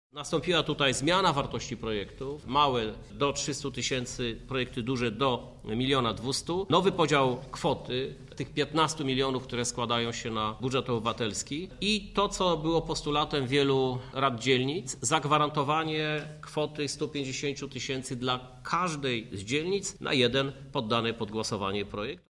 Więcej o zmianach mówi prezydent Lublina, Krzysztof Żuk.